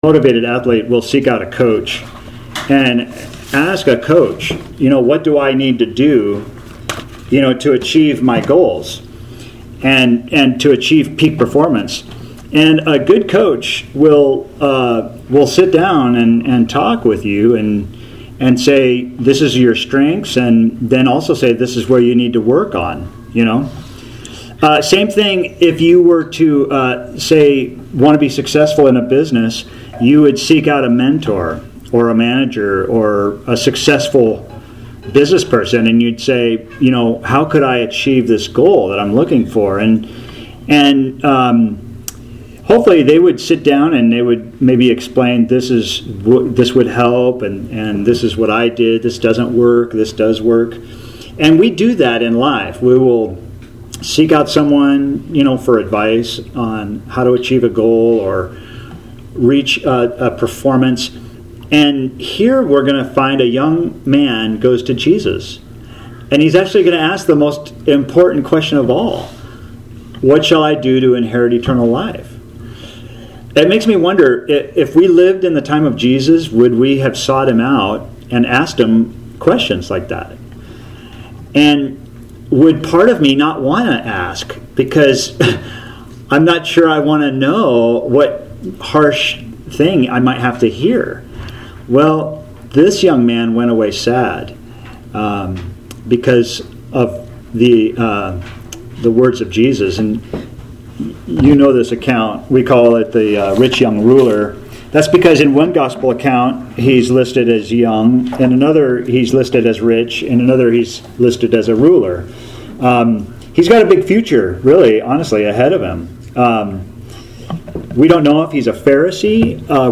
Author jstchurchofchrist Posted on August 1, 2024 Categories Sermons Tags Jesus , Luke - Gospel For All